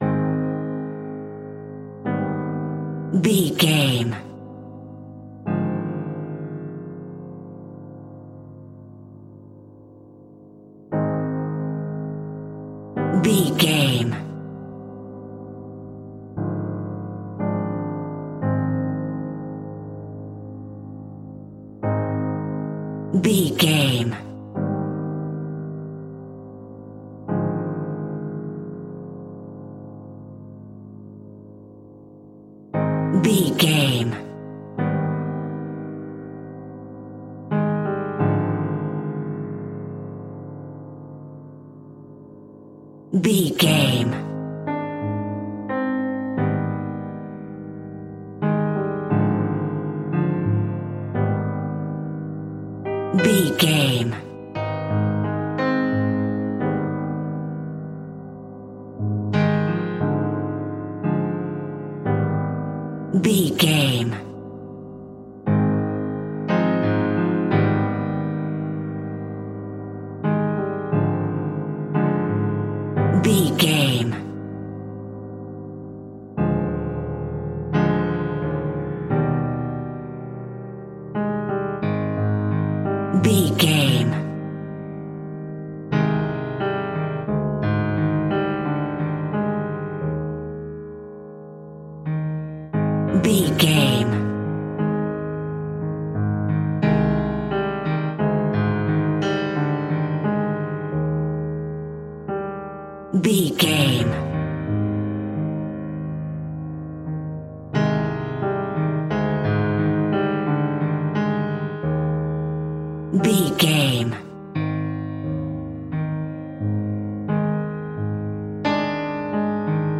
Slow and Scary Piano Music.
Aeolian/Minor
A♭
ominous
haunting
eerie
Acoustic Piano